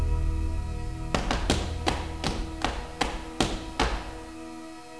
tap.wav